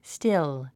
発音
stíl　スティゥ